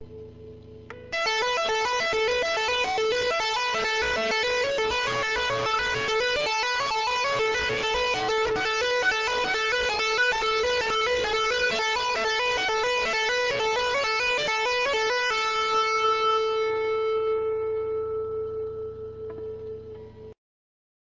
Tapping
Verzerrt